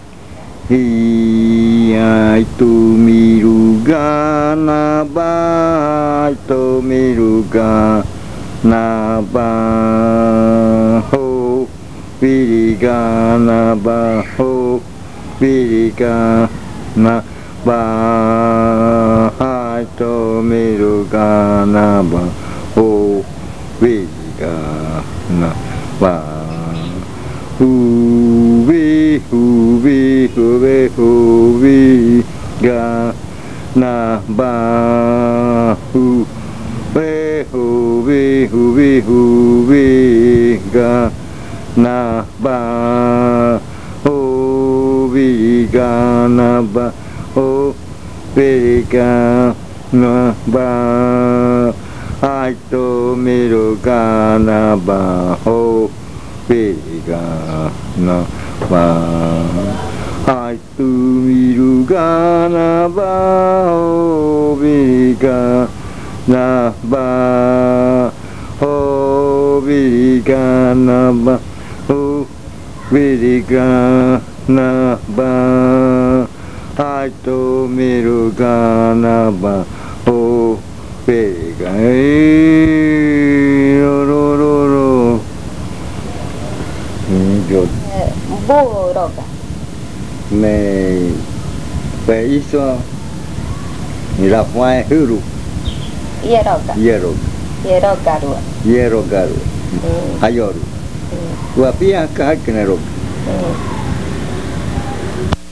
Canto de baile de culebra
Snake dance chant
original cassette 05B-2